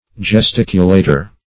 Gesticulator \Ges*tic"u*la`tor\, n.